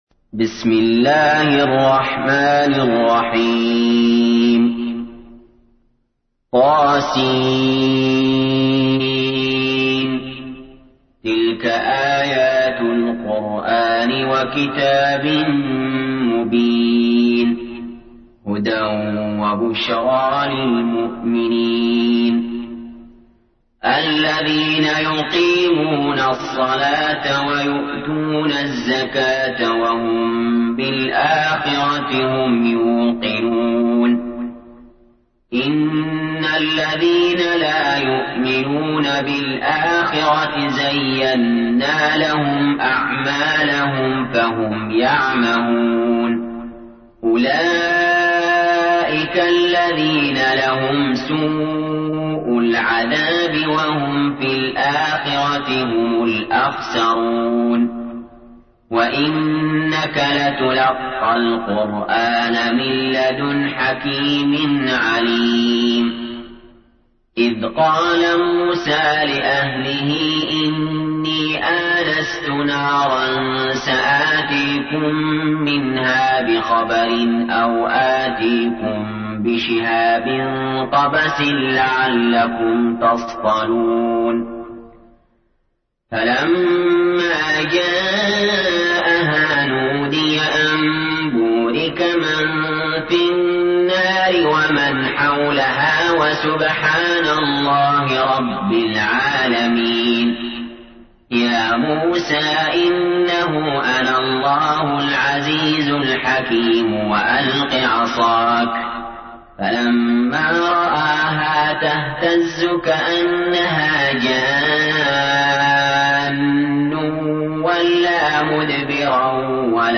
تحميل : 27. سورة النمل / القارئ علي جابر / القرآن الكريم / موقع يا حسين